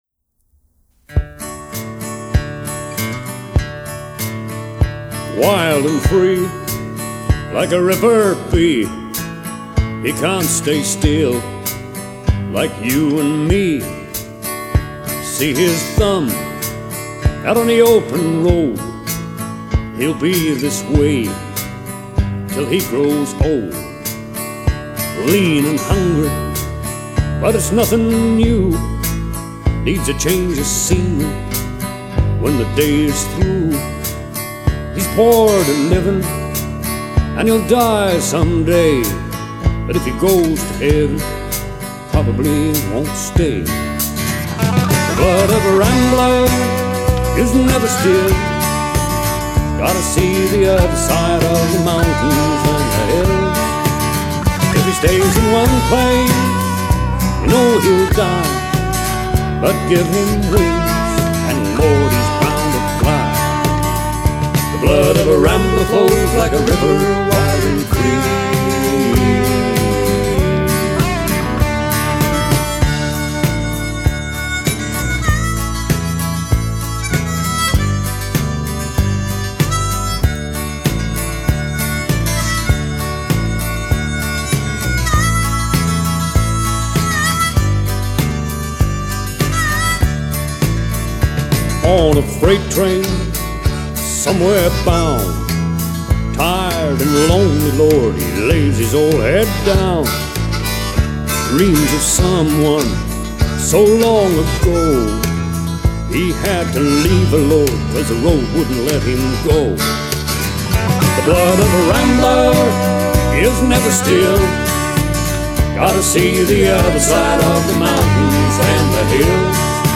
Outlaw Genre